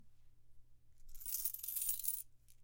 Небольшое количество монет быстро высыпаются
nebolshoe_kolichestvo_monet_bistro_visipayutsya_uyk.mp3